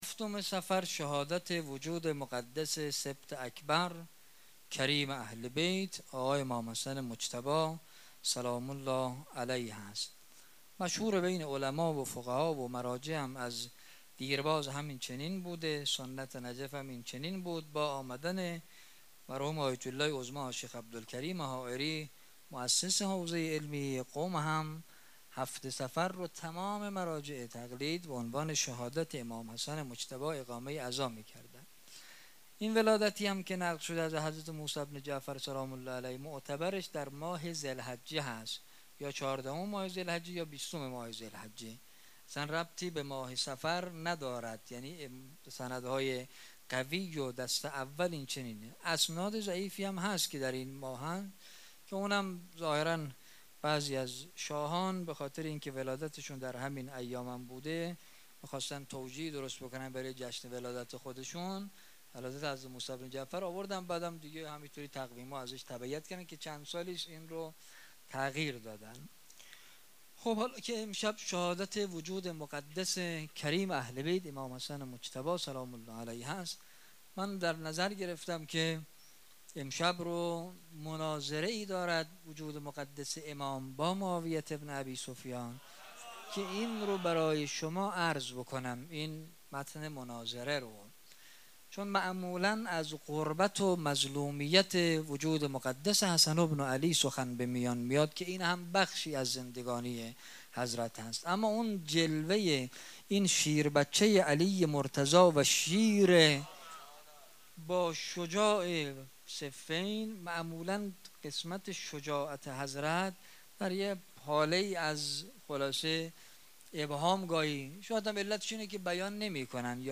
ششم صفر 95 - ريحانة الحسين - سخنرانی